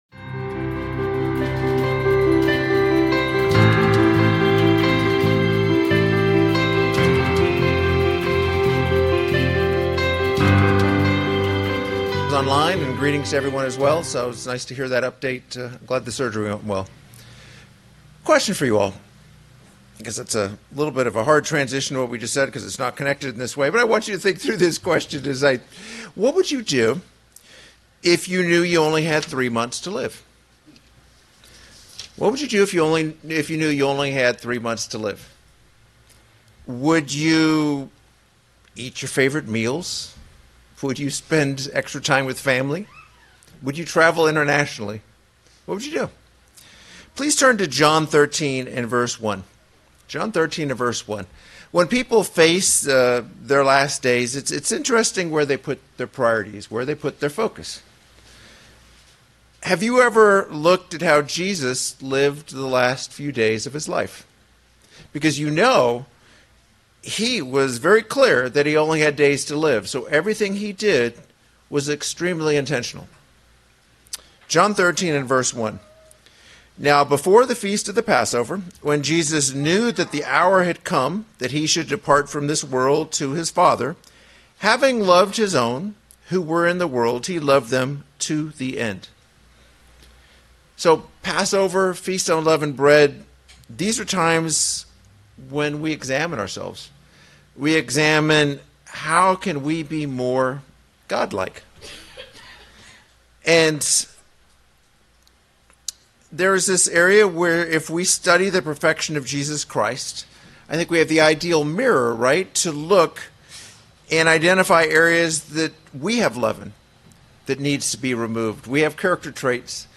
This sermon explores the theme of Godly love, focusing on how Jesus Christ exemplified Agape love during the last days of His earthly life.